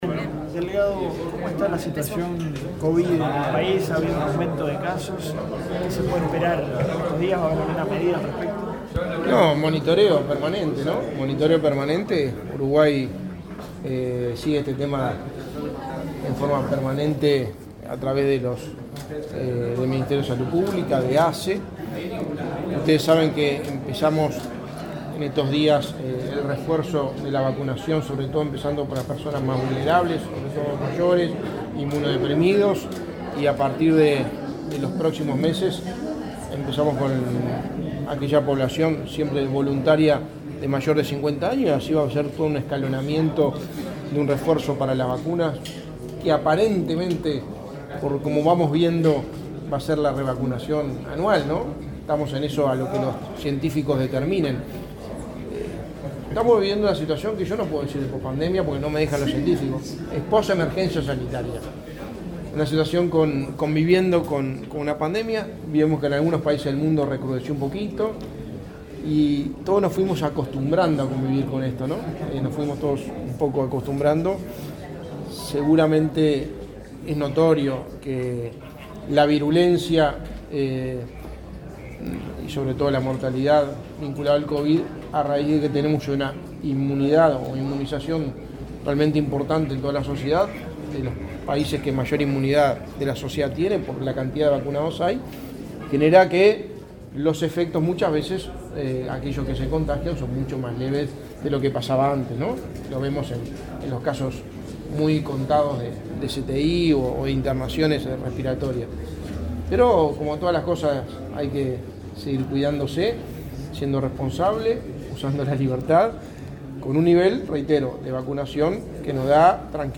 Declaraciones del secretario de Presidencia, Álvaro Delgado
Declaraciones del secretario de Presidencia, Álvaro Delgado 27/12/2022 Compartir Facebook X Copiar enlace WhatsApp LinkedIn El intendente de Flores, Fernando Echeverría; el subsecretario de Turismo, Remo Monzeglio, y el secretario de Presidencia de la República, Álvaro Delgado, participaron del lanzamiento de la 30,º edición del Festival del Lago Andresito le Canta al País, realizado este martes 27 en Montevideo. Luego, Delgado dialogó con la prensa.